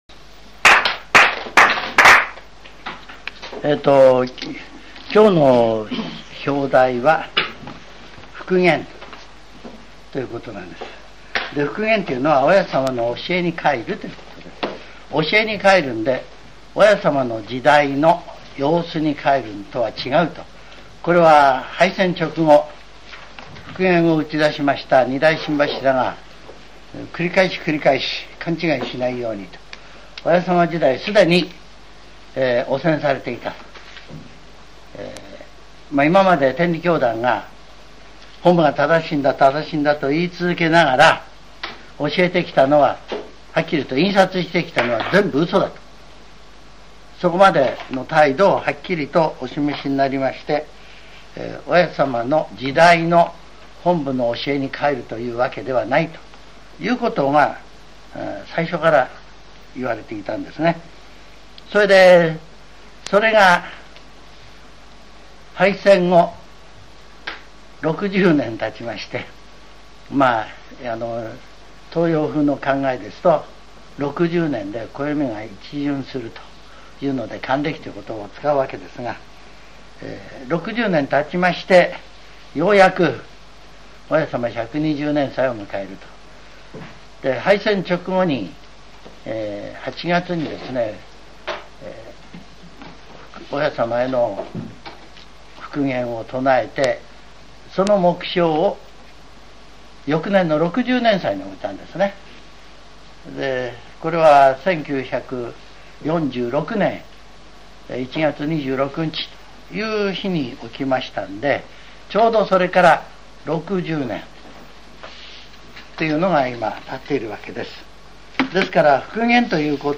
全70曲中49曲目 ジャンル: Speech